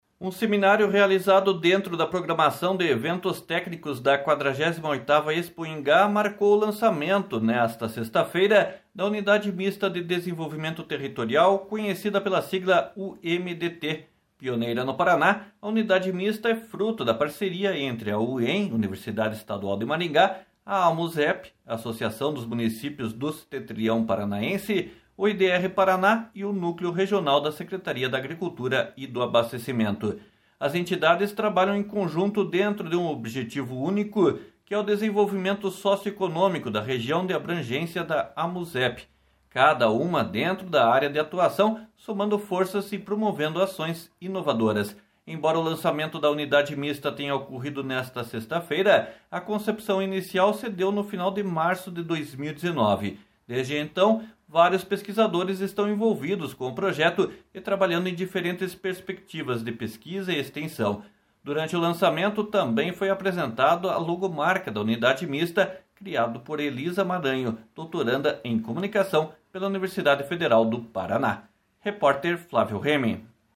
Um seminário realizado dentro da programação de eventos técnicos da 48ª Expoingá marcou o lançamento, nesta sexta-feira, da Unidade Mista de Desenvolvimento Territorial, conhecida pela sigla UMDT.